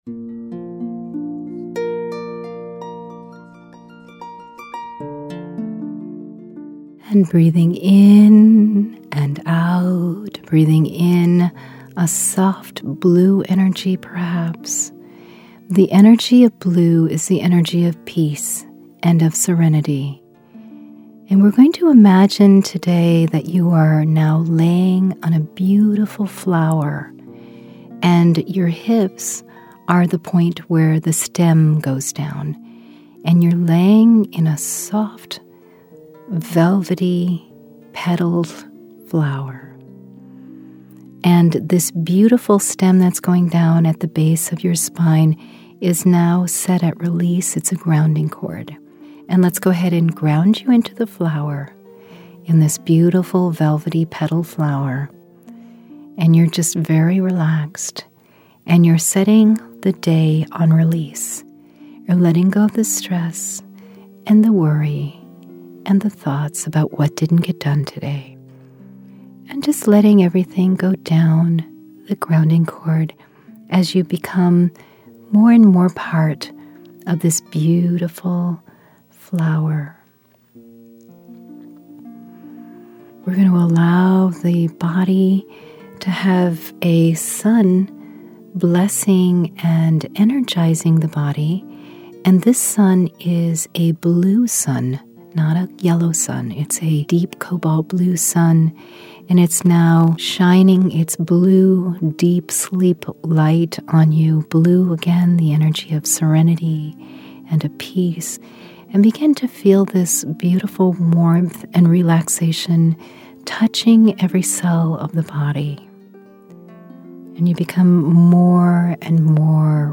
3 Q5 Meditations: